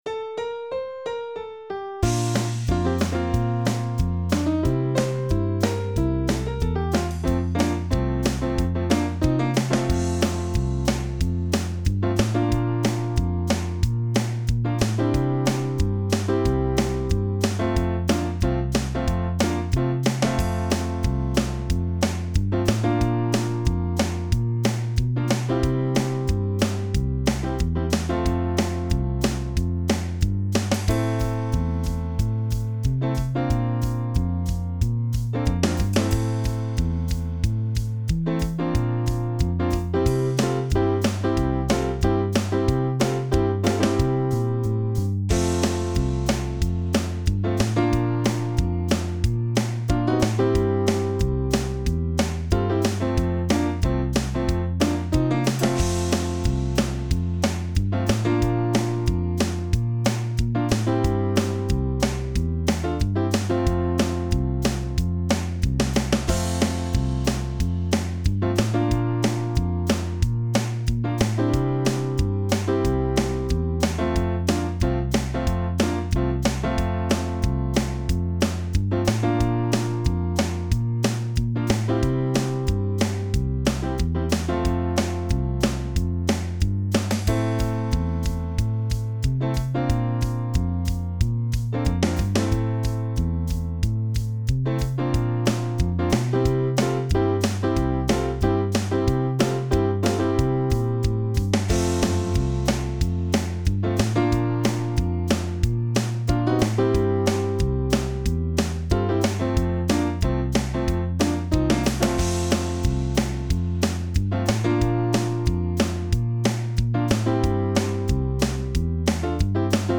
Oostfreesland pur (Playback)